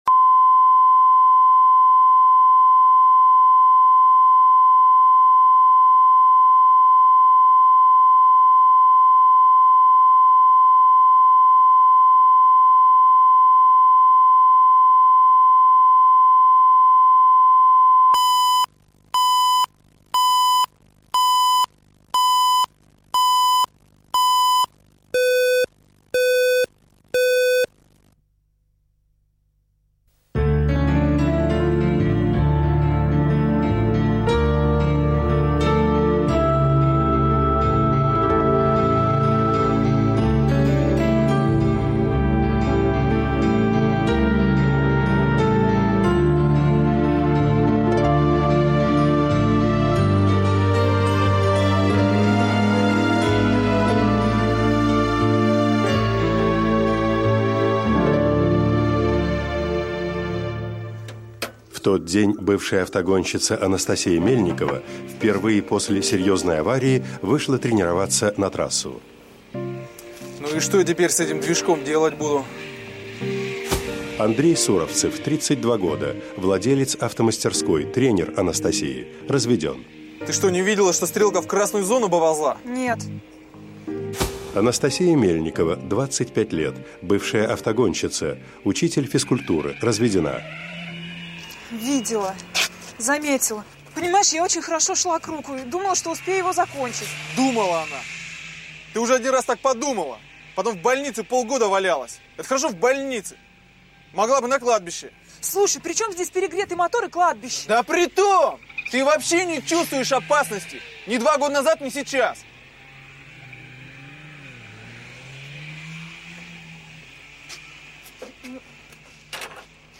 Аудиокнига Неженское дело | Библиотека аудиокниг
Прослушать и бесплатно скачать фрагмент аудиокниги